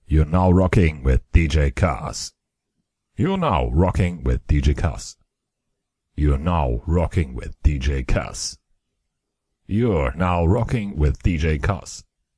以口语为主
Tag: 口语 电子 循环 慢节奏